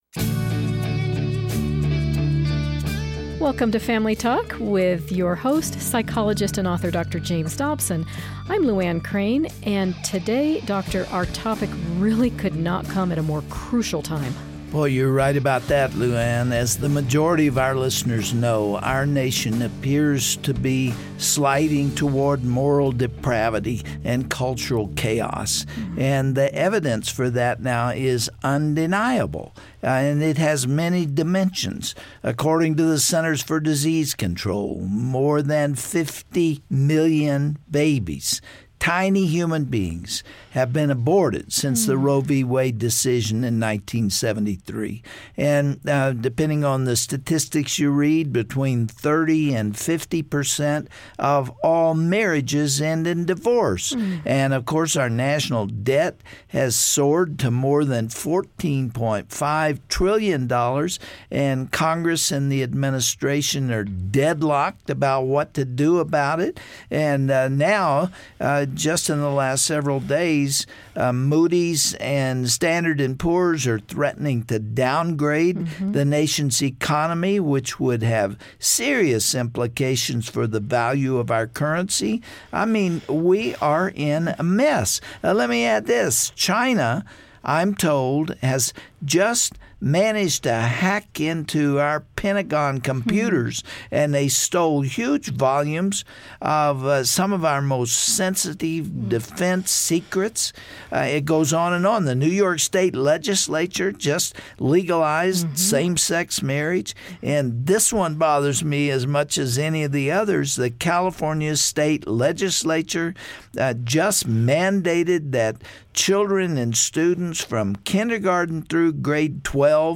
How should Christians respond? Dr. Dobson is joined today by Texas Governor Rick Perry, who describes how leaders around the country are gathering together next month to pray for our hurting nation.